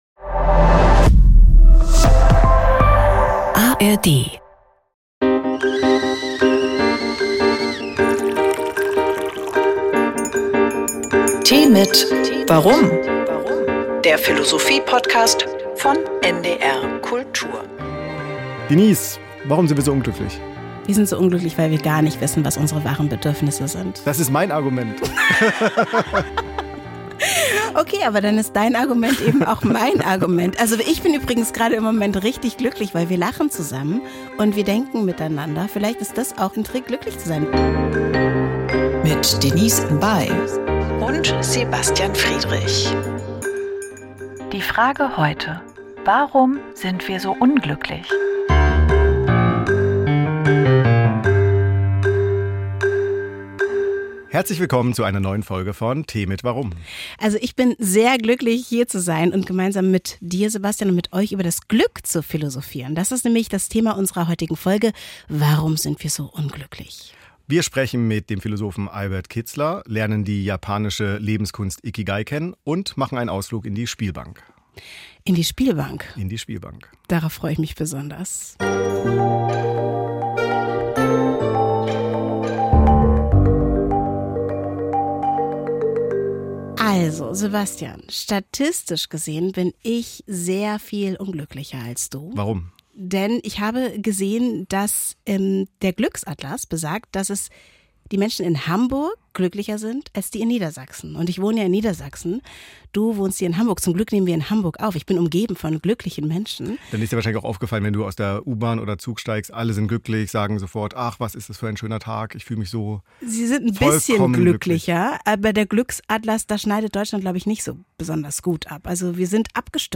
Ein Poker-Dealer spricht über die Jagd nach dem Glück im Casino.